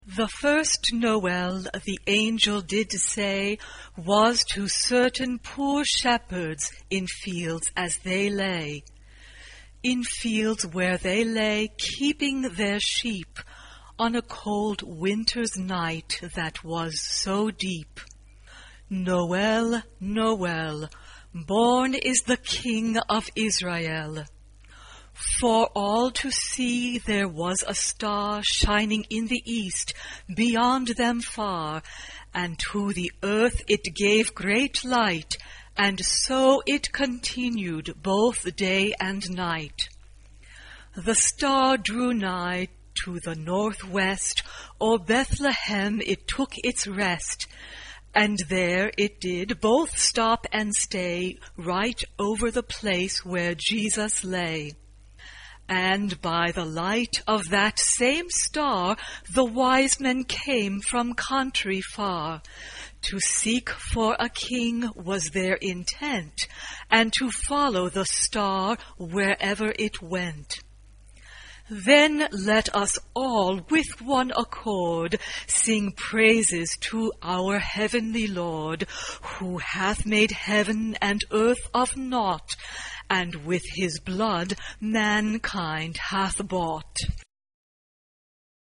Genre-Stil-Form: weltlich ; geistlich ; traditionell ; Weihnachtslied ; Lied Charakter des Stückes: unbeschwert Chorgattung: SATB (4 gemischter Chor Stimmen )
Tonart(en): D-Dur